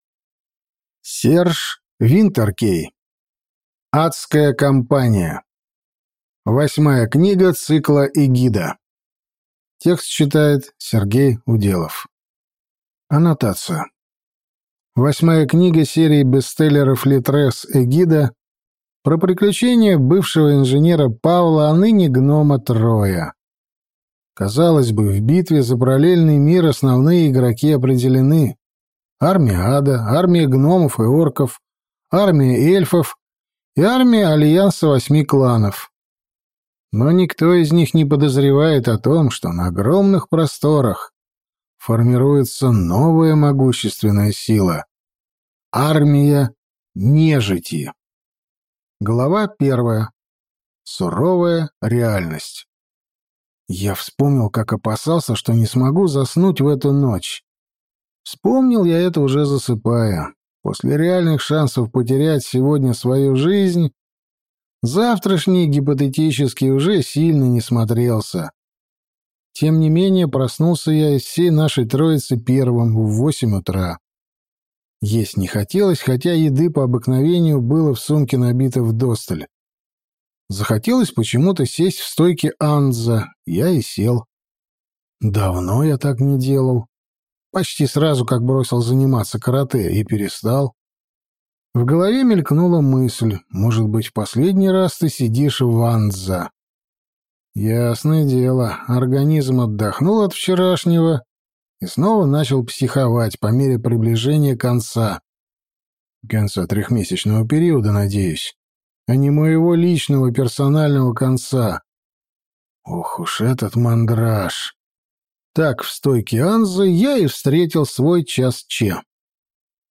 Аудиокнига Адская кампания | Библиотека аудиокниг
Прослушать и бесплатно скачать фрагмент аудиокниги